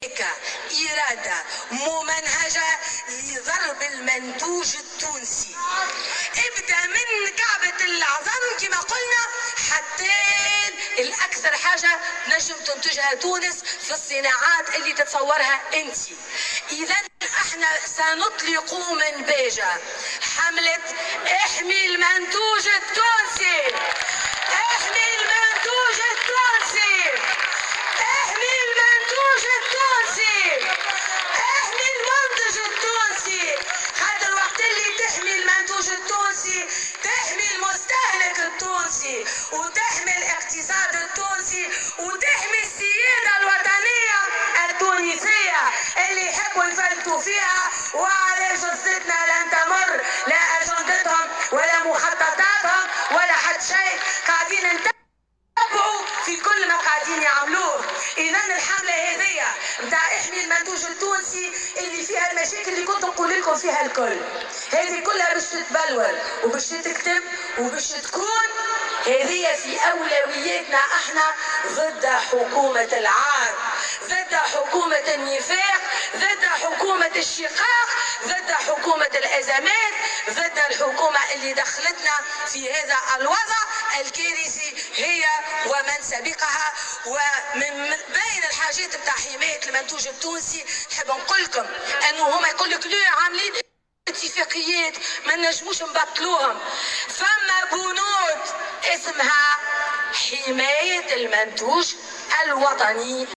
La présidente du PDL, Abir Moussi à déclaré lors du rassemblement protestataire organisé hier à Béja, le lancement d’une campagne ayant pour but de protéger les produits tunisiens.